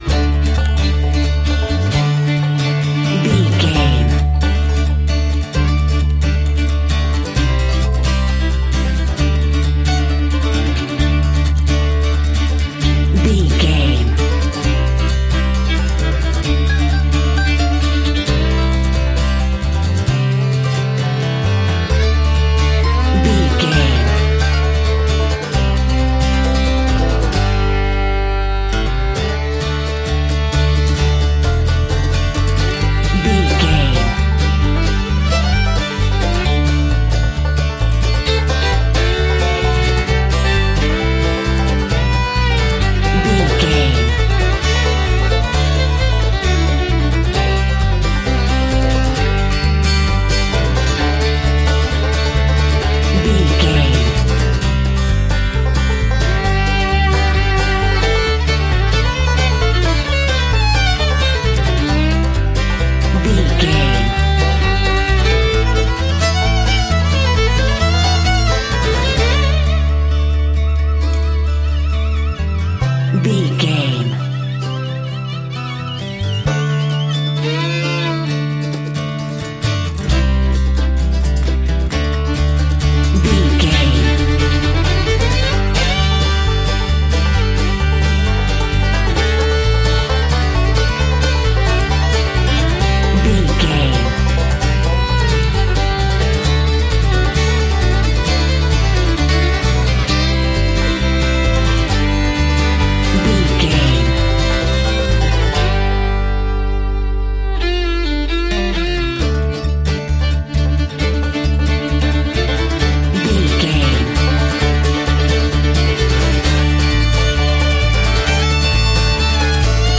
Ionian/Major
acoustic guitar
banjo
bass guitar
violin
Pop Country
country rock
bluegrass
folk
blues
happy
uplifting
driving
high energy